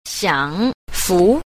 6. 享福 – xiǎngfú – hưởng phúc
xiang_fu.mp3